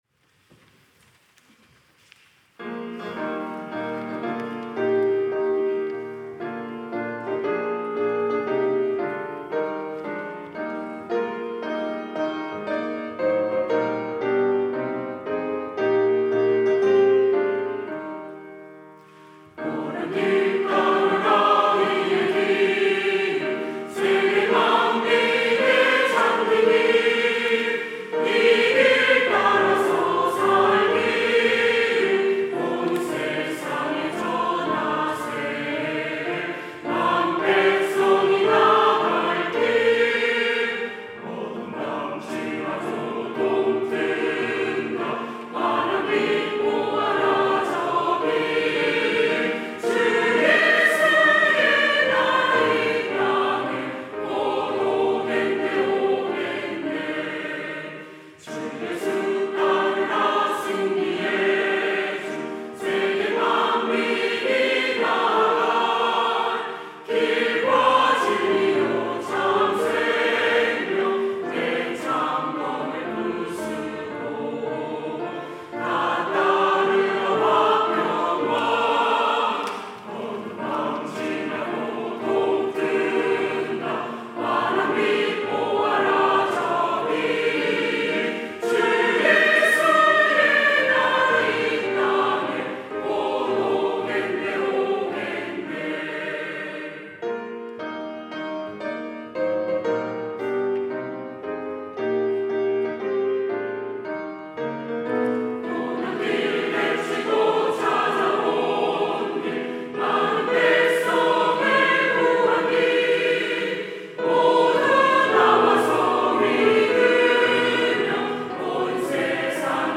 찬양대 가브리엘